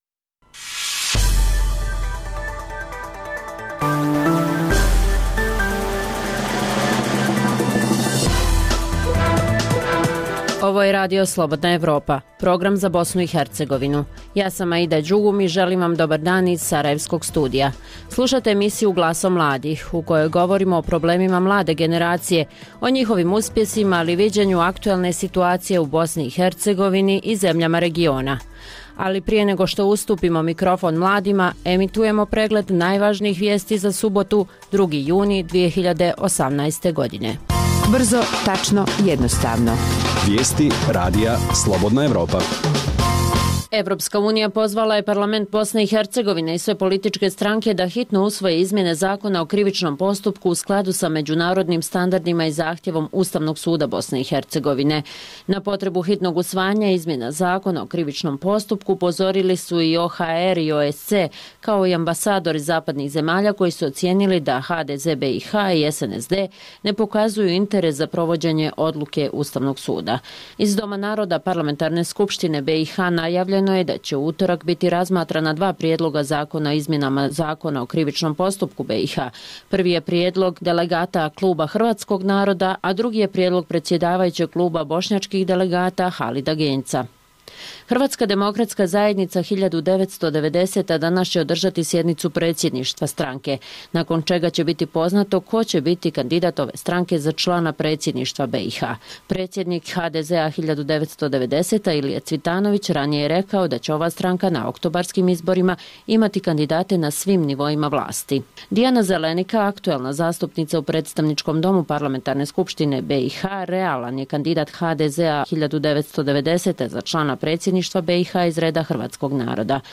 Donosimo priču i o tome da li će biti produžen Protokol prema kojem studenti iz BiH i dalje mogu studirati u Sloveniji bez plaćanja školarine, te razgovor sa mladim aktivistom iz Srbije o suočavanju sa prošlošću na ovim prostorima.